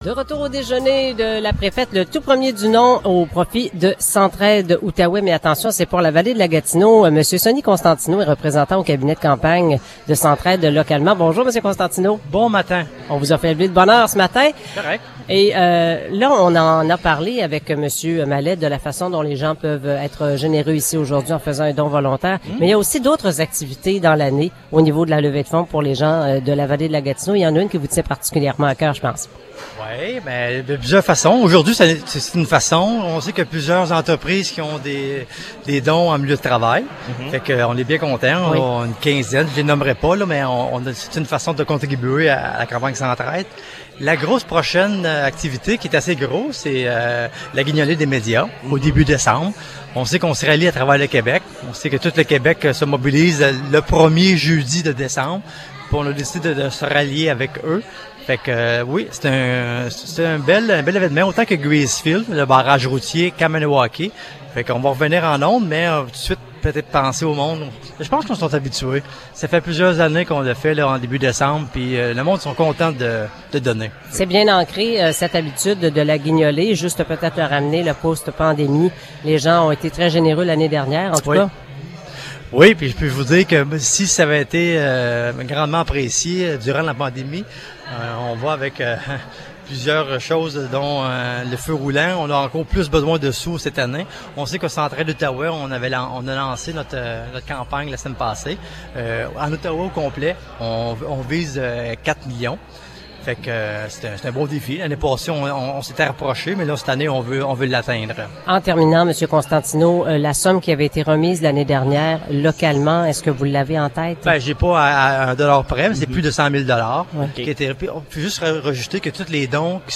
Entrevue
en direct du déjeuner de la préfète